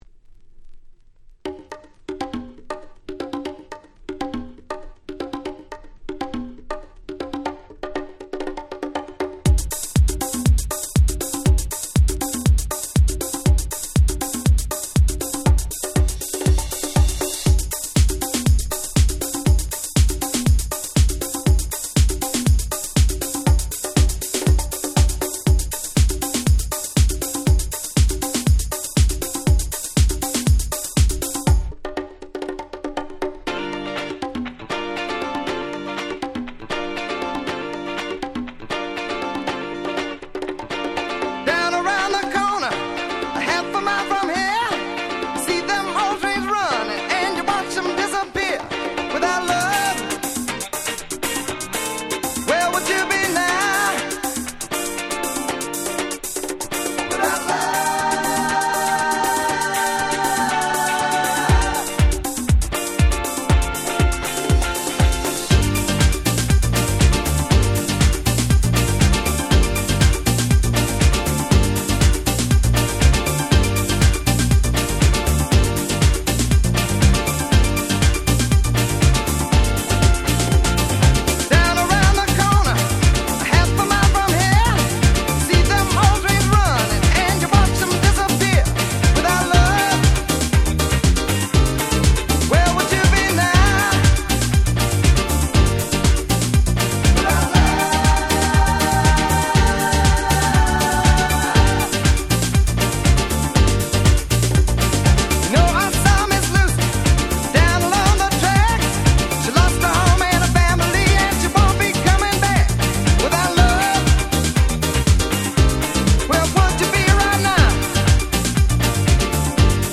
緩いHouse Beatで最強のRemix !!
Dance Classics